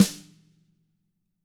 Index of /90_sSampleCDs/ILIO - Double Platinum Drums 1/CD4/Partition B/WFL SNRD